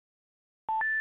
(VICIdial) Heard a lot during both scams and legit customer service I heard this beep with legit Amazon support when an agent picks up
Always Remember what the Answering sounds are for Real support lines such as Amazon where they use the 2 tone beep 2nd audio in my post and most of the time all agents have the same setup for receiving calls.